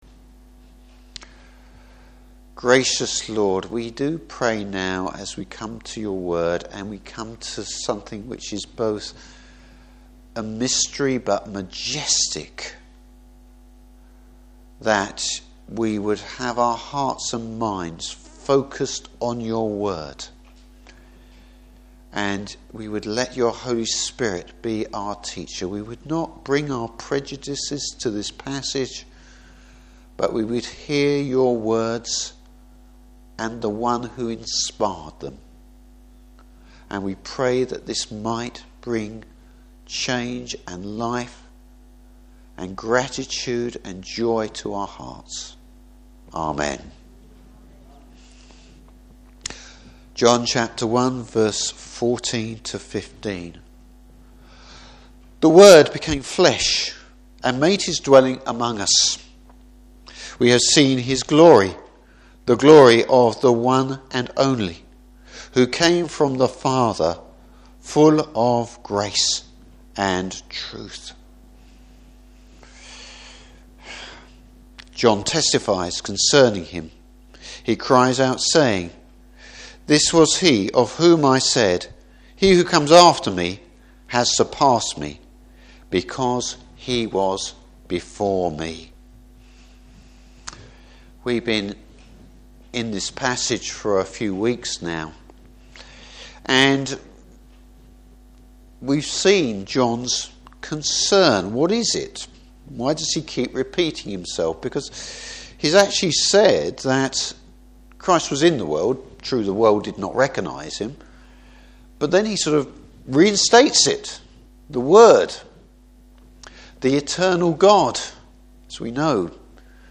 Service Type: Morning Service The humanity and divinity of Christ.